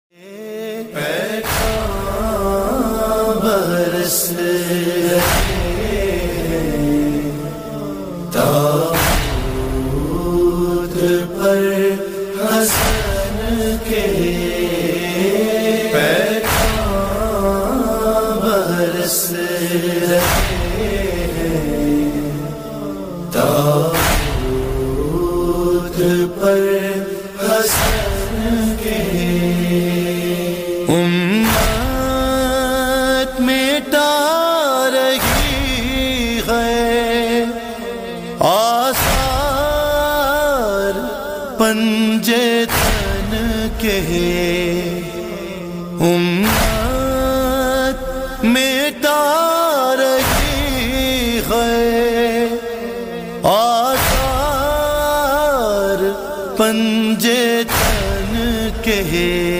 حسینیہ ہال موچی گیٹ لاہور